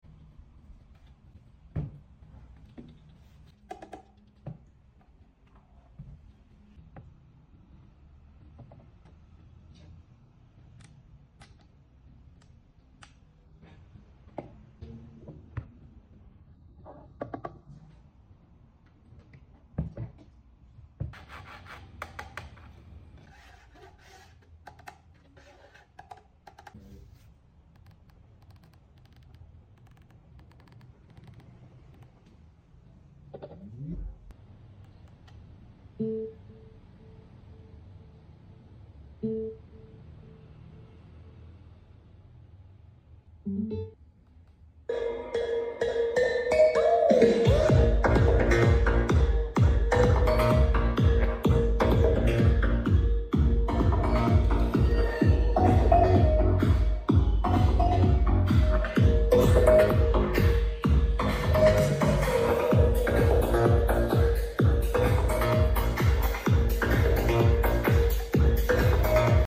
Car Subwoofer + Bluetooth Beast! 🔊 Sound Test In Store!